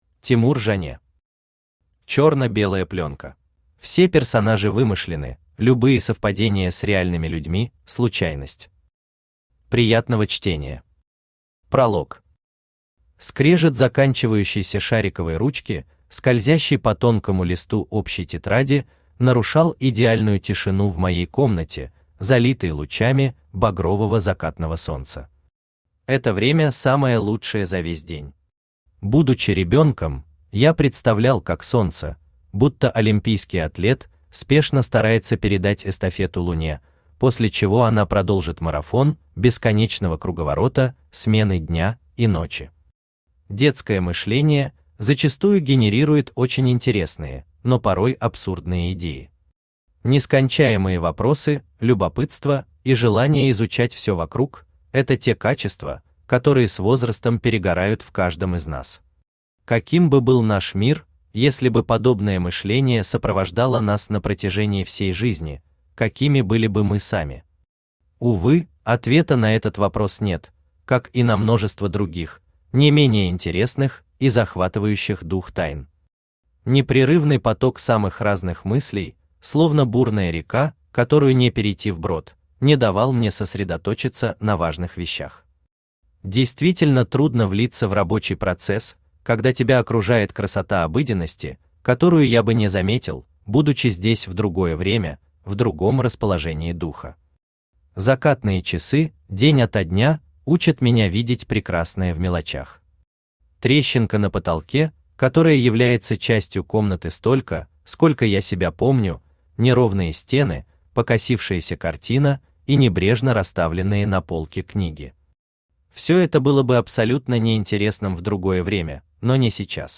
Aудиокнига Черно-белая пленка Автор Тимур Юрьевич Жане Читает аудиокнигу Авточтец ЛитРес.